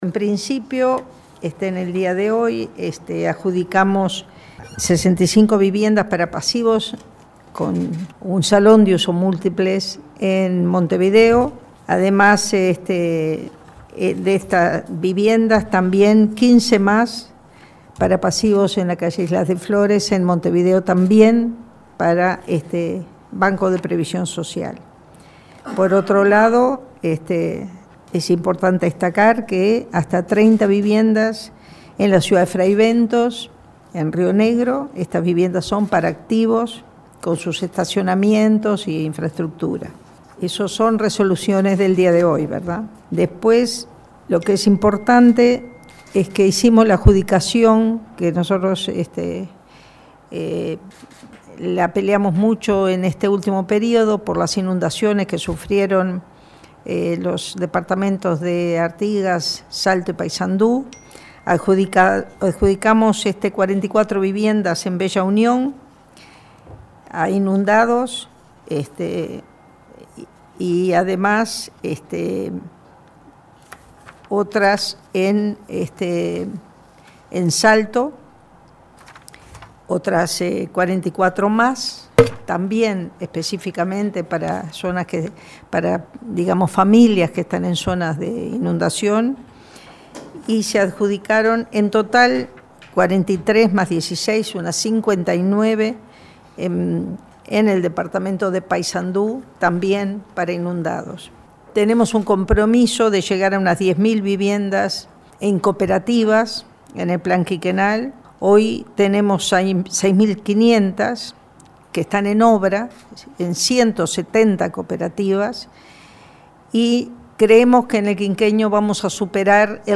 “El Gobierno espera superar la meta de 10.000 viviendas cooperativas en el quinquenio”, destacó este lunes la ministra de Vivienda, Eneida de León, al finalizar el Consejo de Ministros. Detalló, además, que hay 6.500 unidades en obras en 170 cooperativas, 60 % de las cuales están en el interior del país.